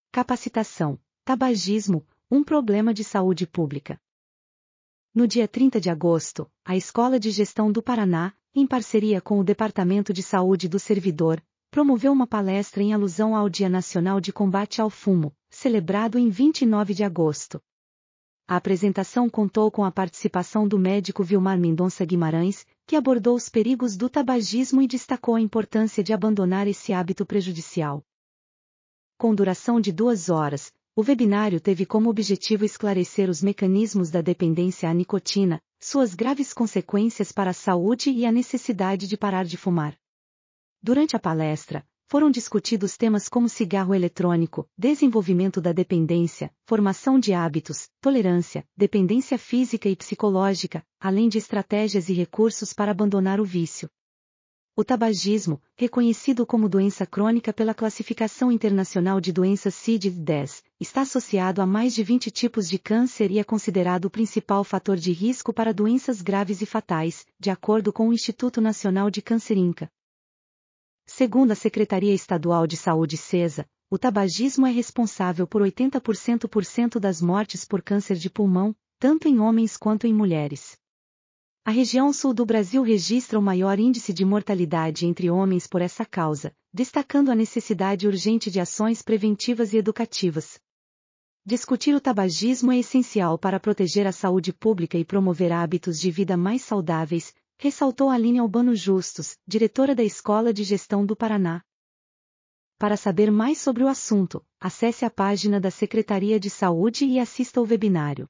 audionoticia_webinar_tabagismo.mp3